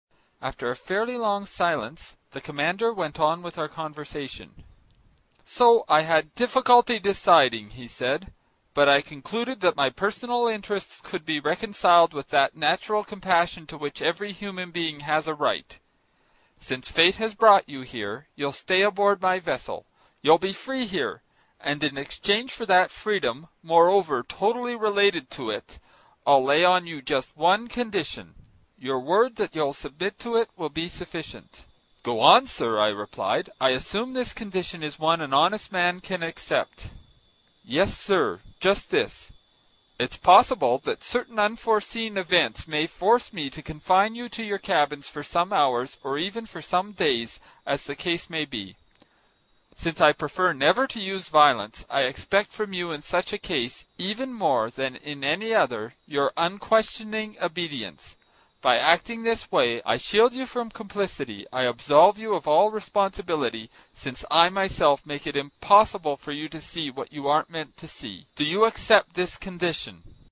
在线英语听力室英语听书《海底两万里》第137期 第10章 水中人(8)的听力文件下载,《海底两万里》中英双语有声读物附MP3下载